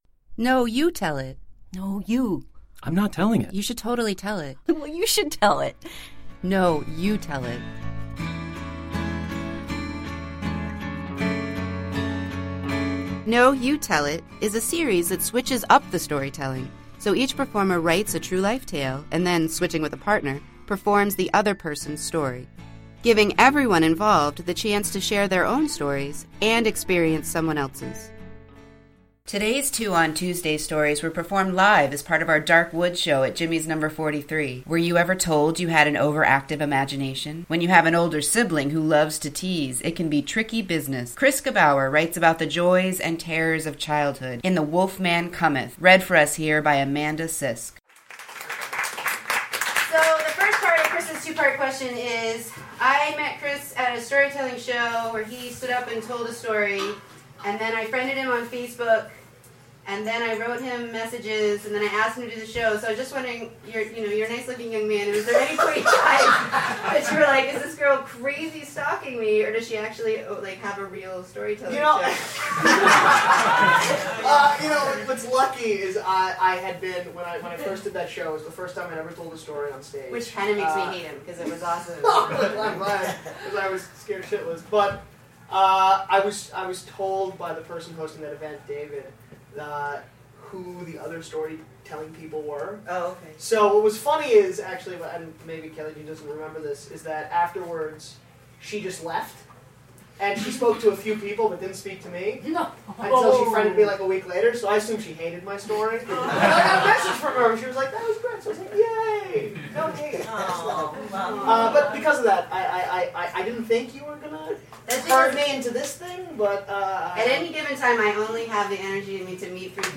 Today’s Two on Tuesday stories were performed live as part of our “Dark Woods” show.